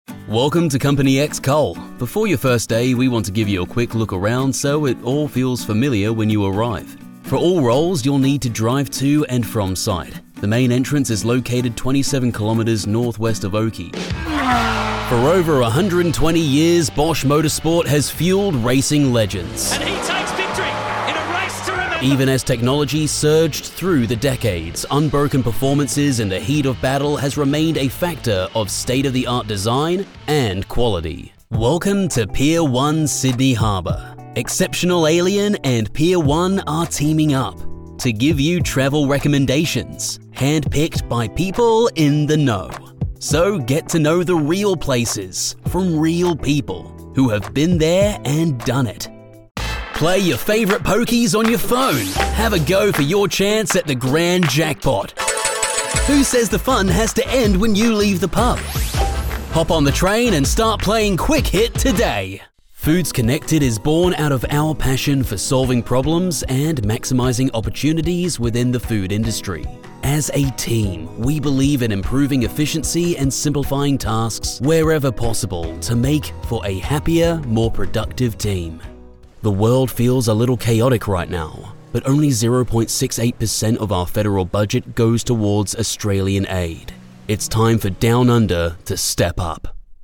Live Announcer
Australian Announcer Bold